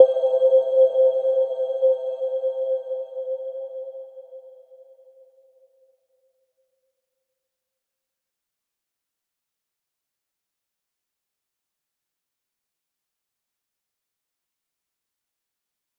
Little-Pluck-C5-f.wav